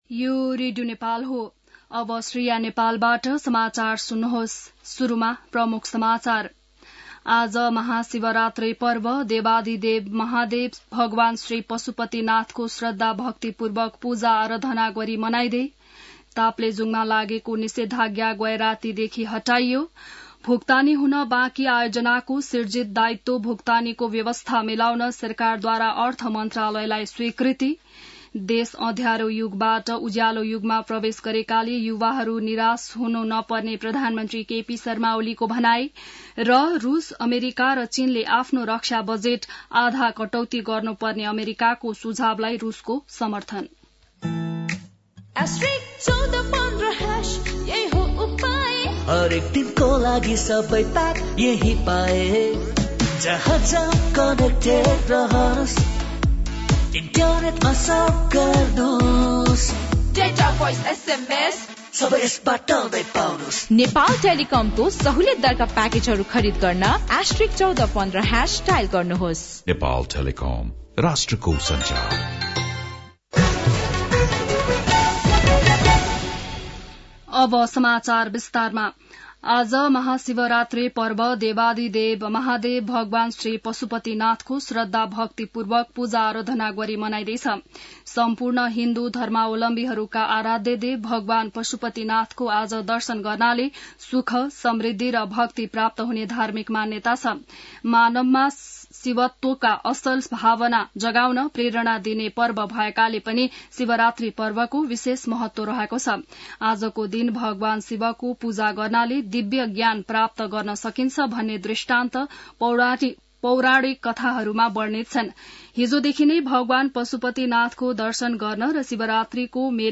बिहान ७ बजेको नेपाली समाचार : १५ फागुन , २०८१